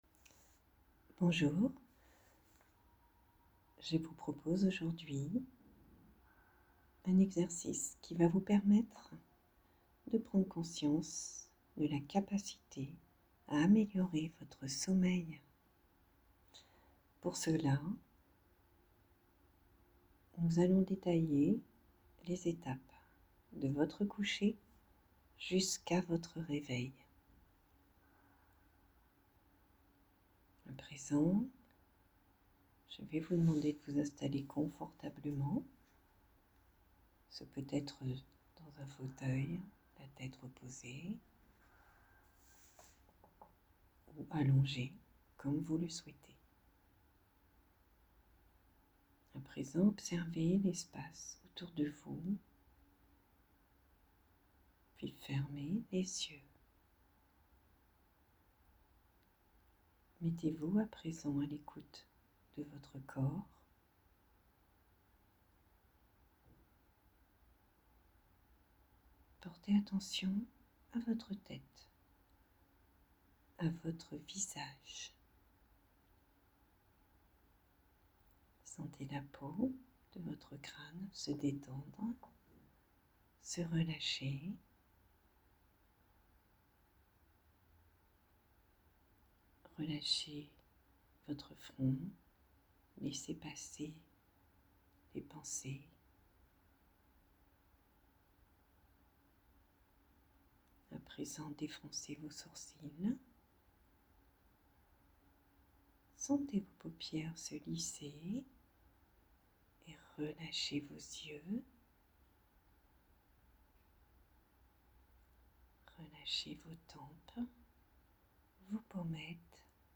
Séance de sophrologie guidée pour le sommeil